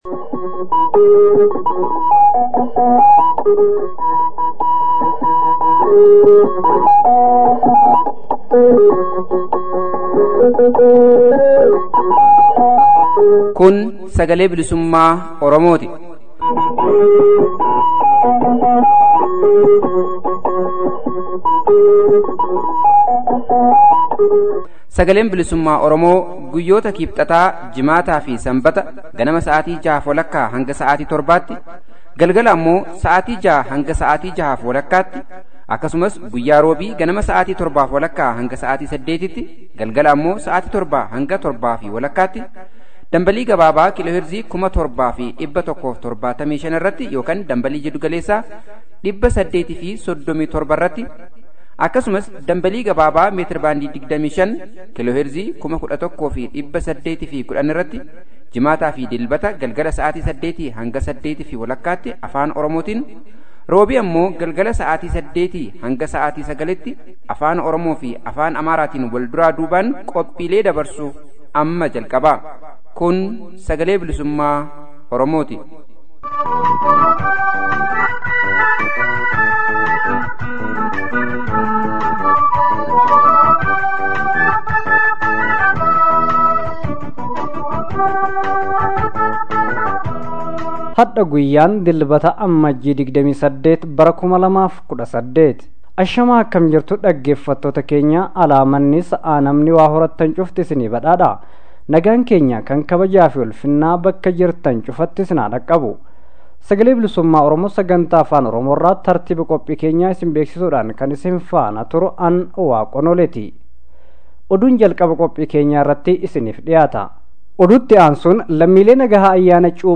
Haala Yaroo fi qabsoo hidhannoo ABOn Baha Oromiyaa keessatti geggessaa turee fi jiru ilaalchisee Marii